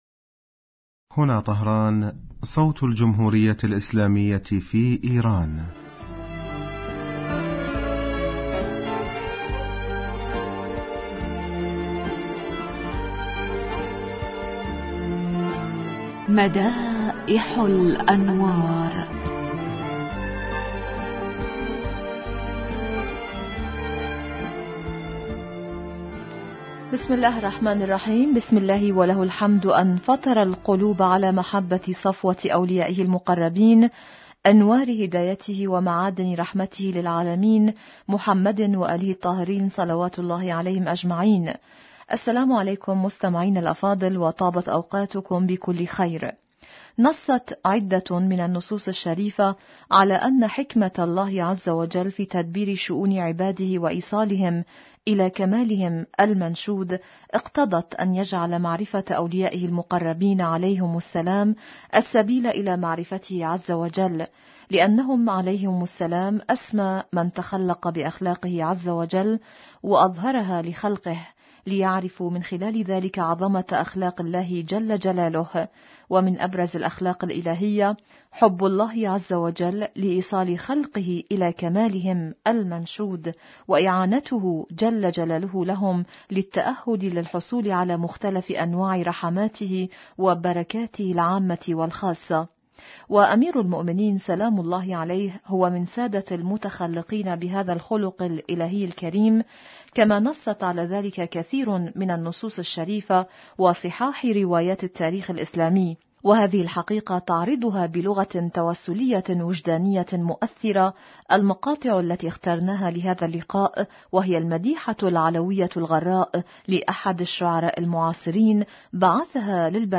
إذاعة طهران- مدائح الانوار: الحلقة 410